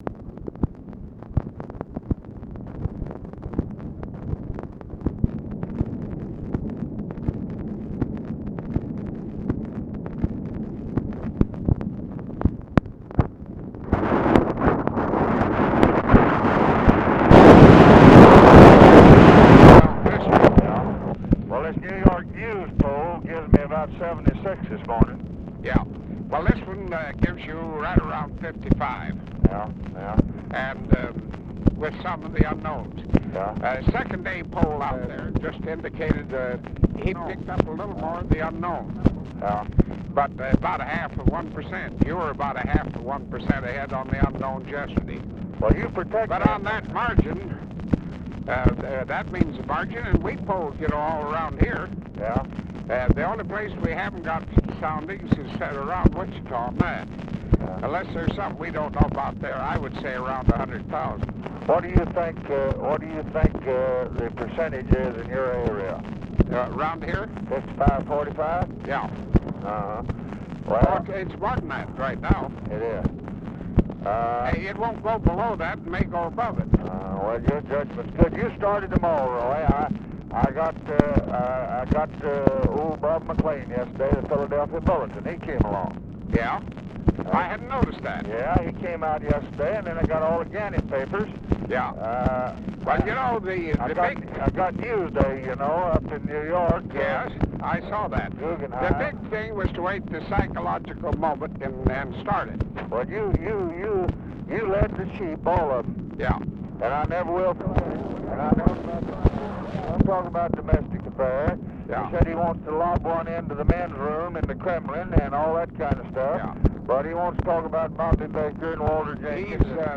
Conversation with ROY ROBERTS and LADY BIRD JOHNSON, October 21, 1964
Secret White House Tapes